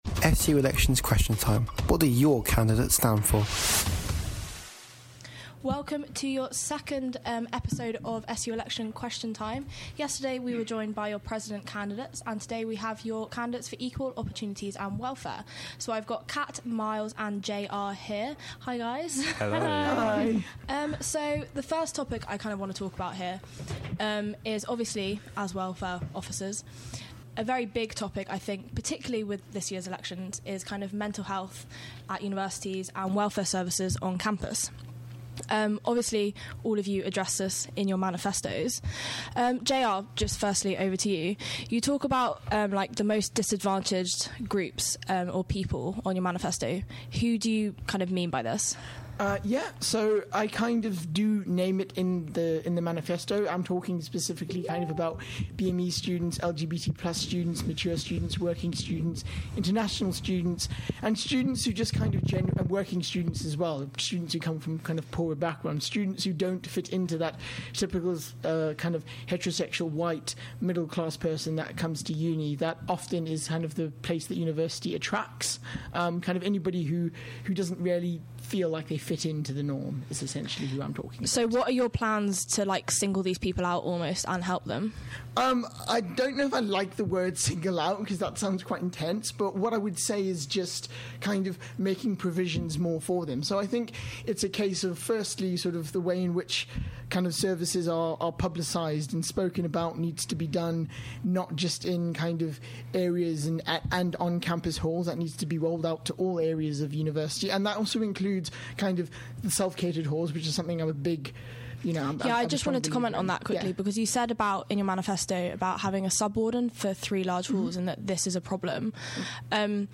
Listen to your candidates for Equal Opportunities & Welfare Officer discuss their manifestos.